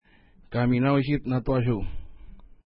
Pronunciation: ka:mi:na:wʃi:t-na:twa:ʃu:
Pronunciation